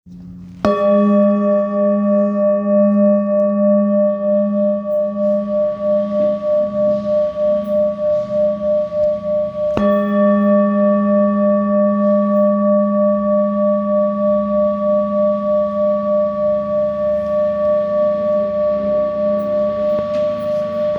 Handmade Singing Bowls-31776
Singing Bowl, Buddhist Hand Beaten, with Fine Etching Carving, Select Accessories
Material Seven Bronze Metal